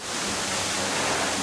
hose.ogg